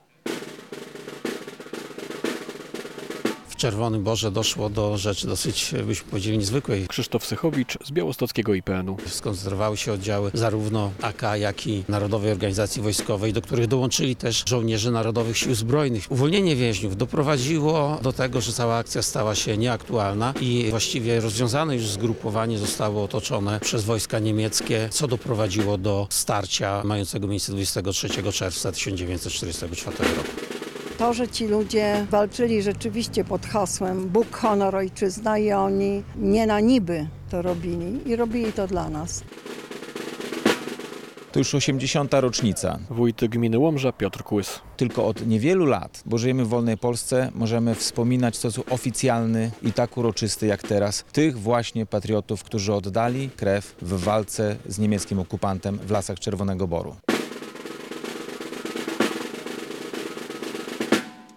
To była największa bitwa partyzancka w rejonie Łomży w czasie II wojny światowej. W 80. rocznicę tych wydarzeń odbyły się uroczystości w Wygodzie.
relacja